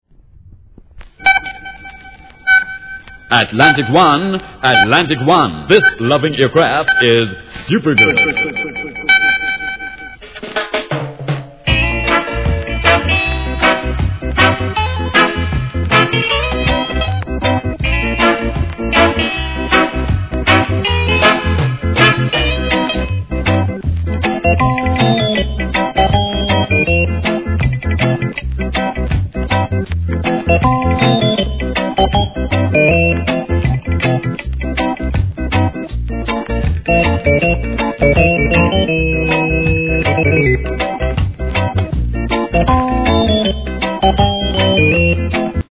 REGGAE
1976年、名INST.物!!!